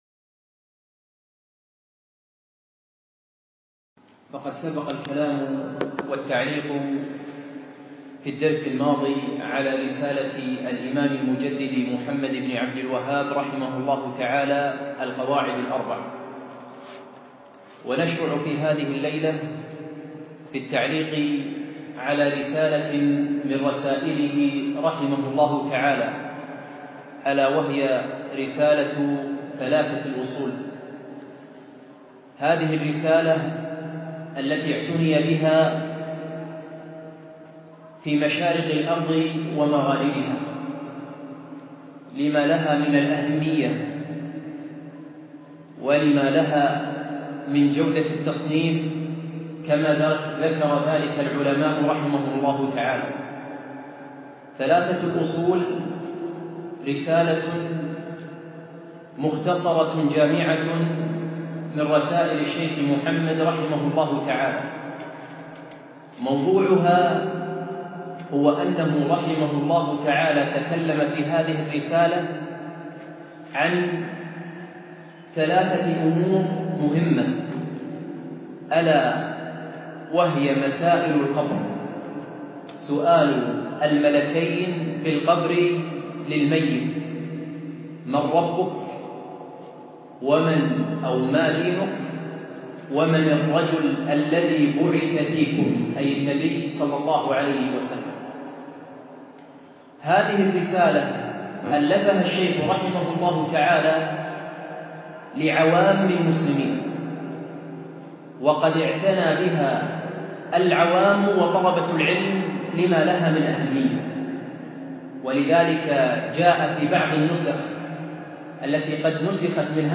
أقيم الدرس في مسجد زين العابدين بمنطقة سعدالعبدالله منقول من قناة مشروع الدين الخالص